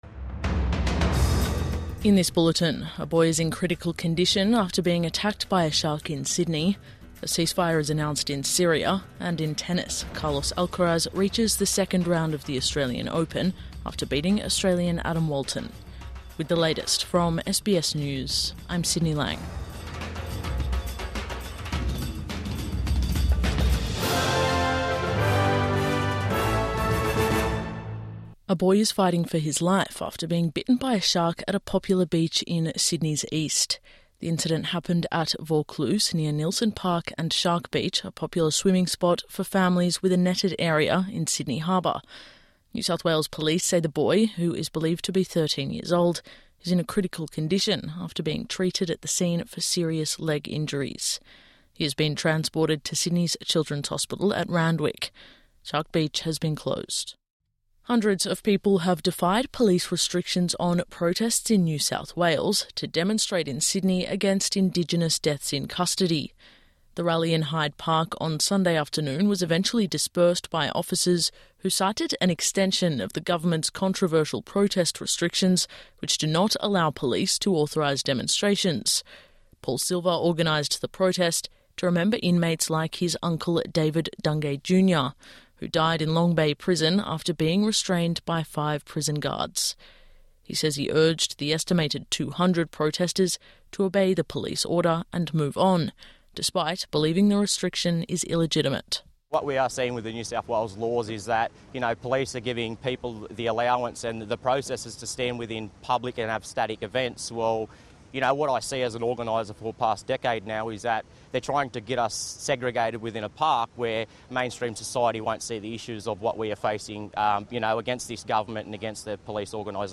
Boy critically injured by shark at Sydney beach| Morning News Bulletin 19 January 2026